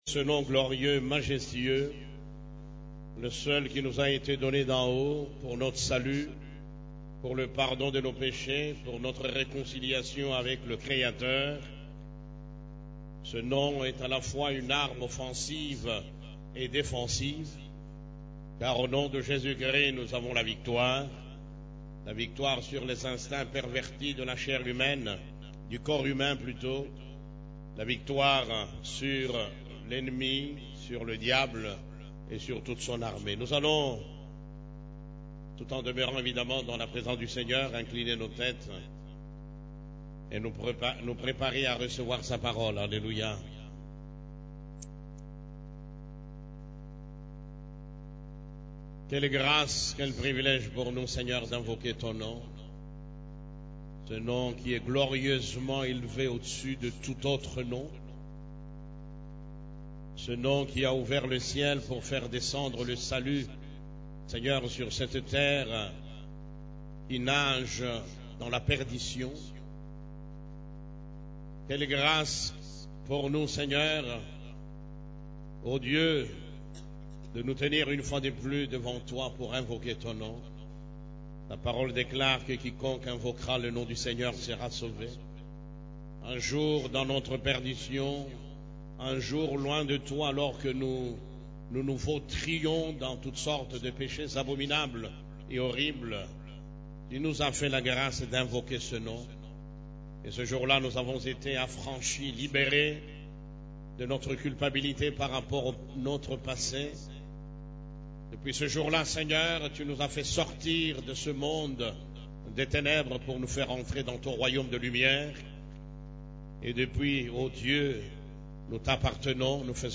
CEF la Borne, Culte du Dimanche, Le type de disciples que Jésus choisit et utilise (2)